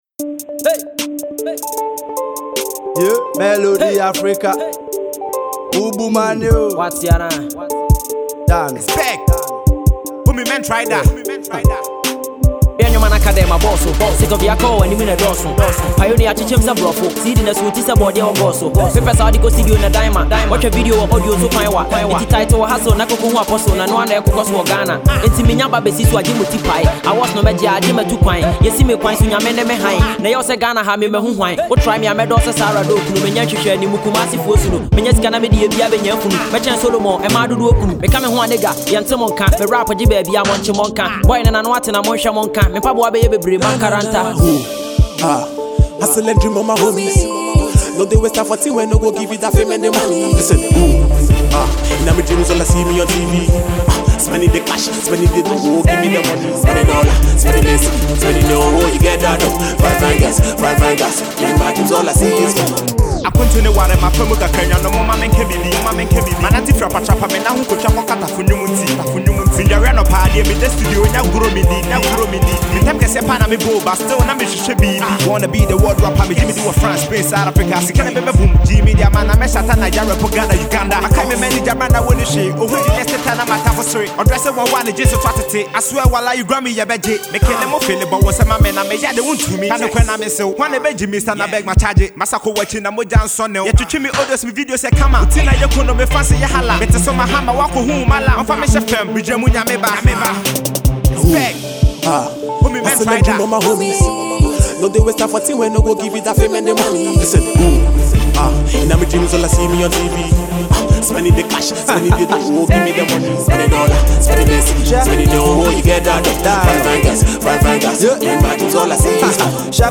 Hip-Hop
Street Banger